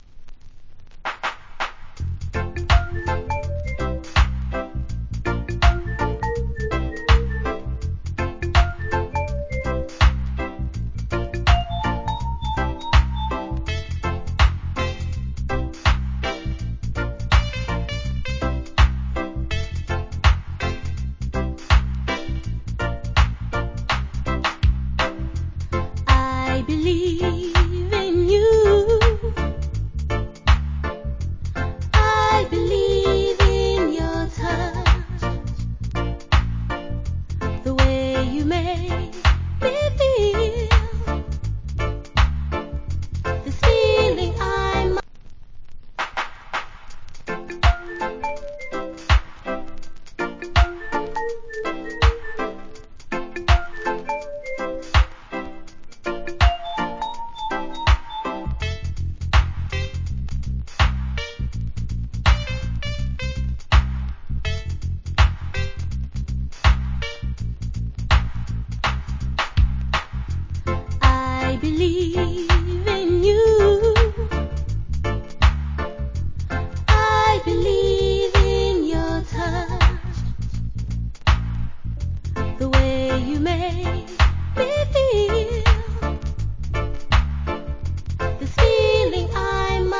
90's. Nice Female UK Lovers.